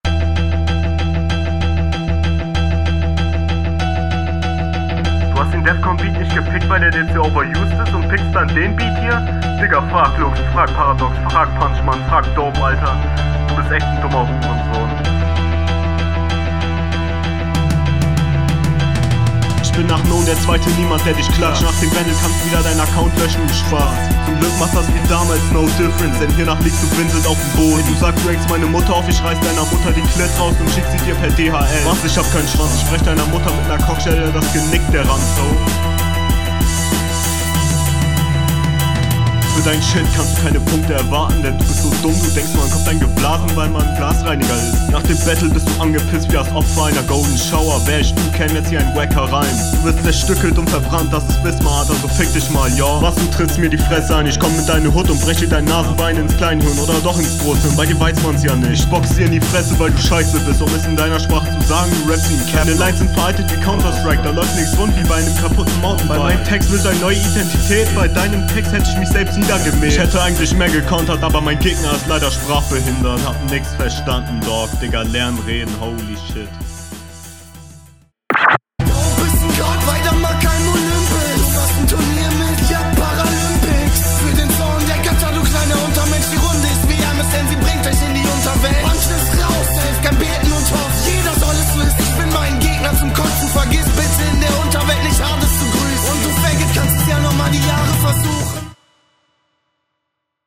kann mehr verstehen als beim gegner
Selbes Problem, der Beat ist viel zu laut.